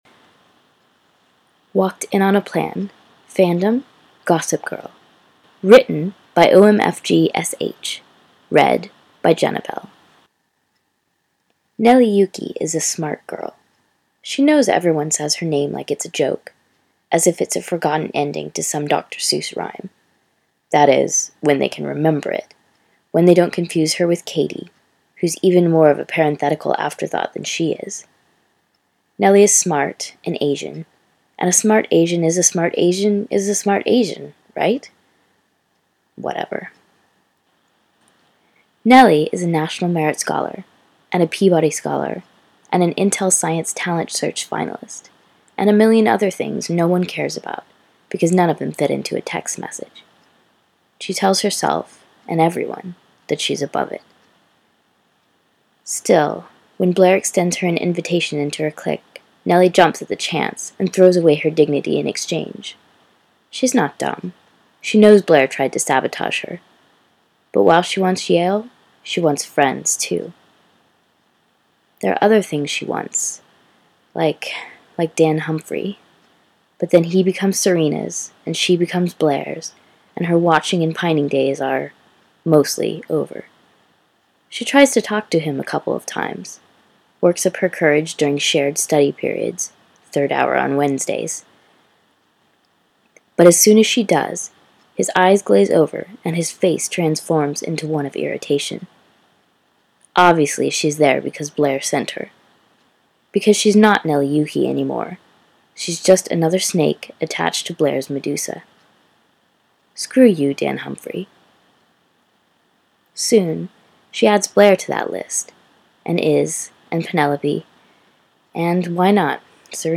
Podfic: 7 shorts for the "Awesome Ladies Ficathon"